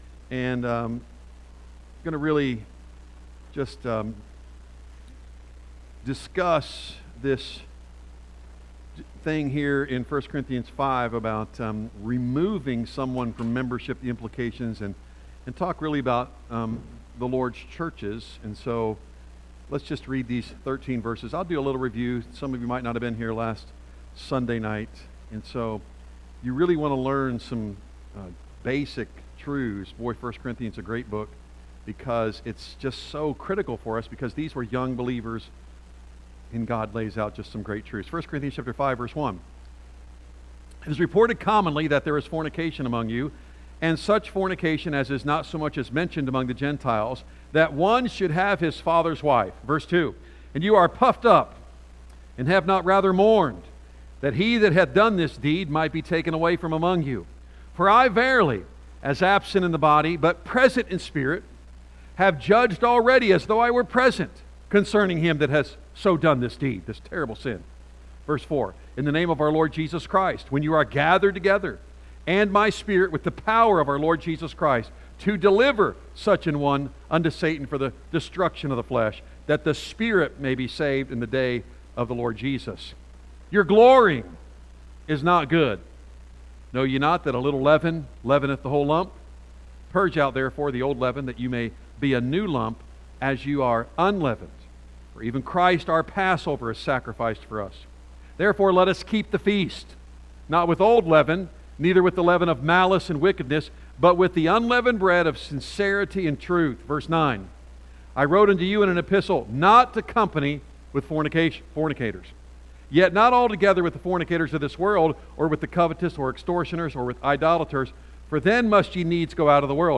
A message from the series "1 Corinthians."